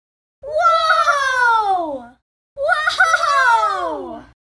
woah.mp3